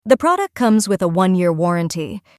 /tts/examples_azure/t/